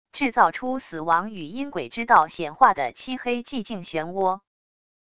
制造出死亡与阴鬼之道显化的漆黑寂静漩涡wav音频